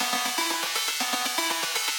SaS_Arp04_120-E.wav